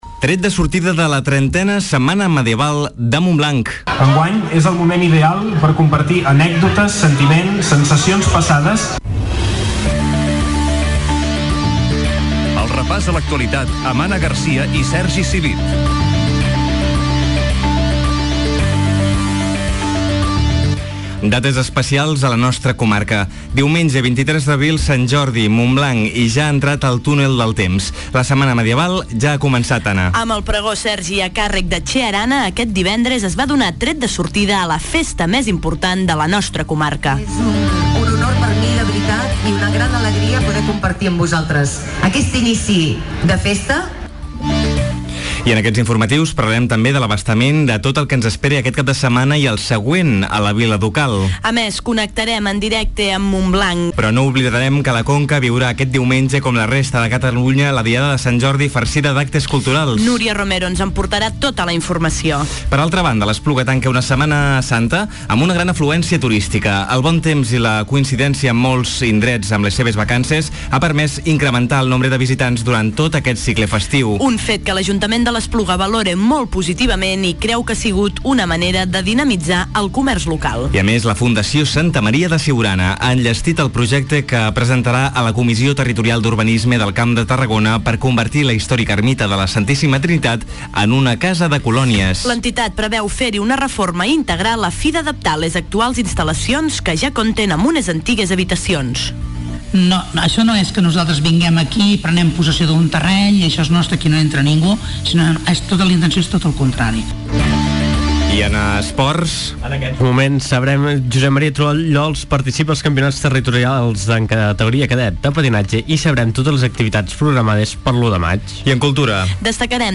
INFORMATIU CAP DE SETMANA 22 I 23 D'ABRIL